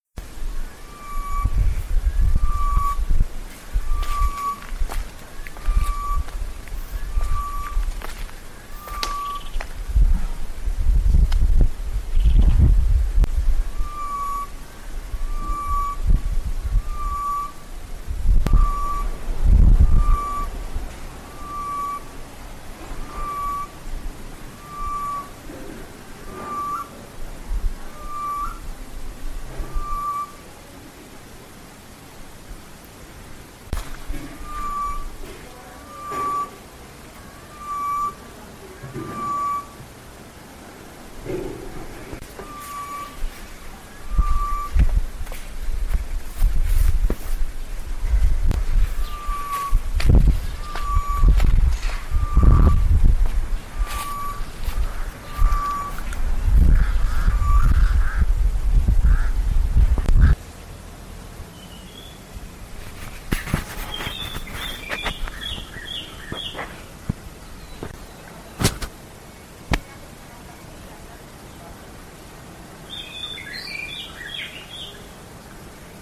• Rebero-Kigali birds morning 20th June 2015
02_birdsnoon20june.mp3